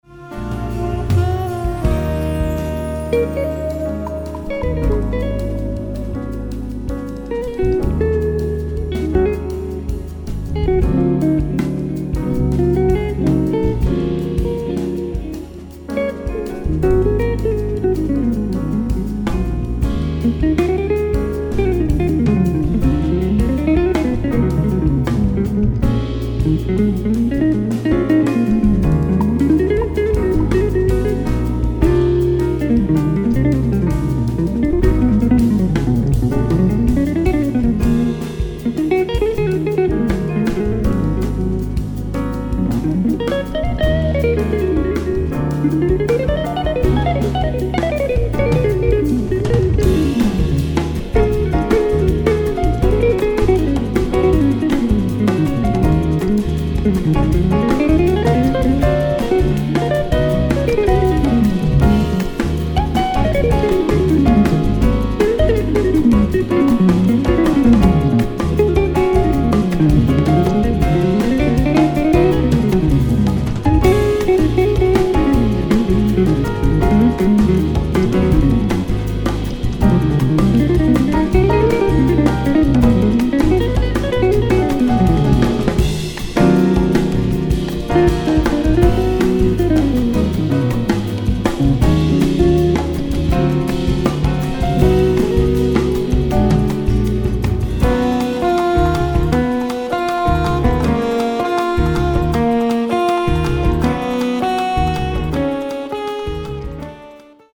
Beaucoup trop court et trop rare avec ce son,mais bon.....